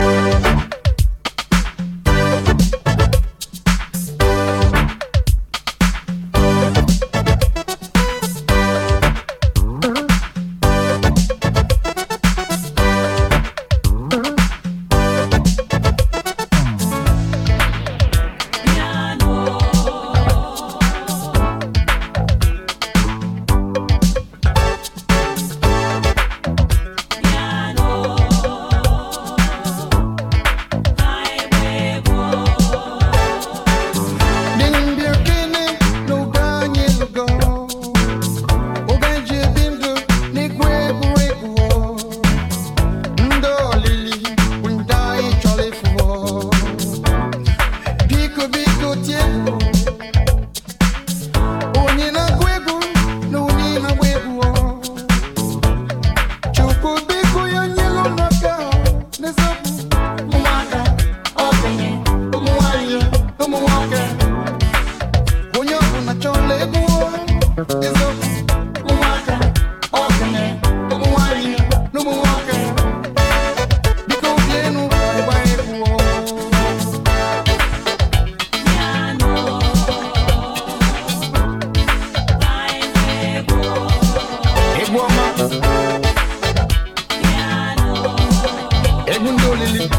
ジャンル(スタイル) NU DISCO / RE-EDIT